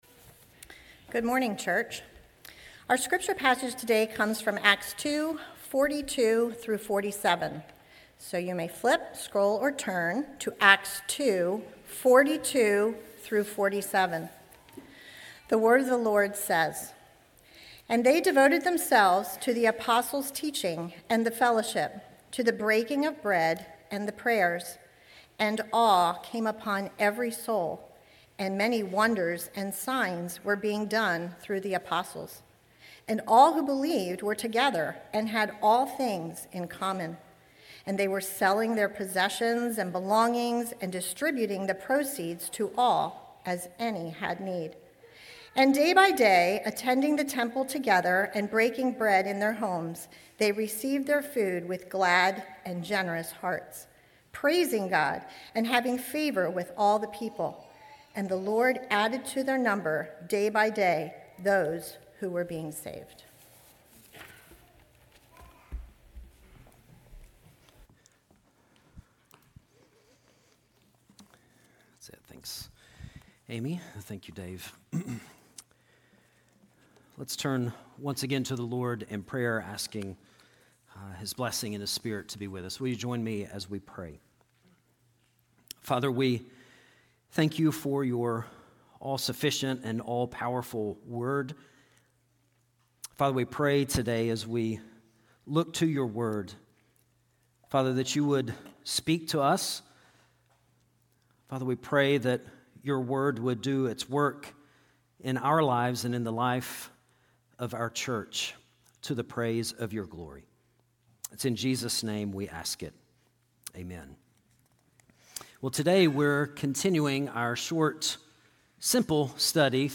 sermon4.19.26.mp3